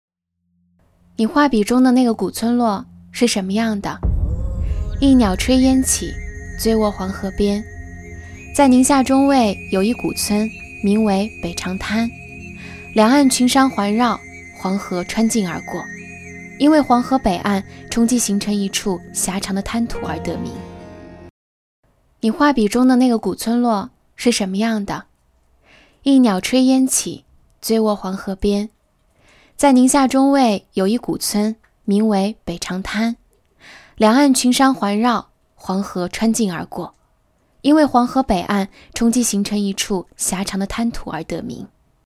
纪录片-女34-素人风-旅游宣传 黄河.mp3